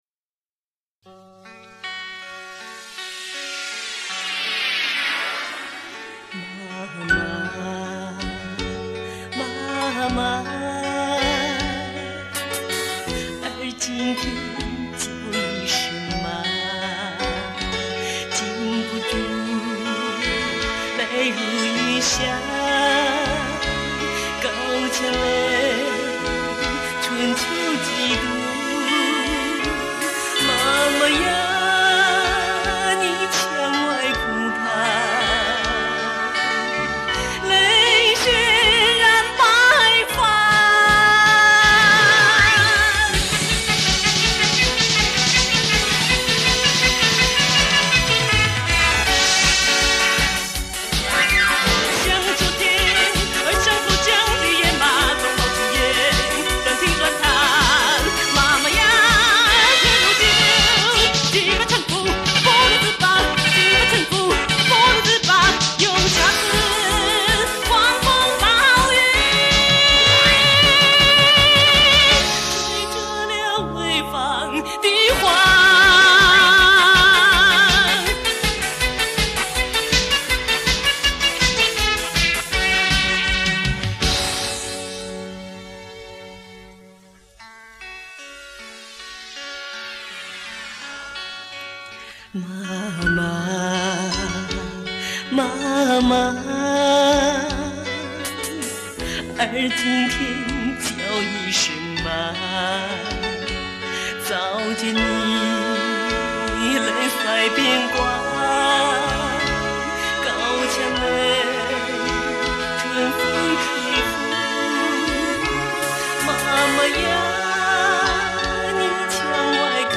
小乐队伴奏和配器，是当年的风格